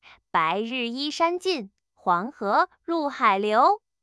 multilingual speech-style-transfer text-to-speech voice-cloning
CosyVoice2-0.5B-Scalable Streaming Speech Synthesis with Large Language Models
"task": "zero-shot voice clone",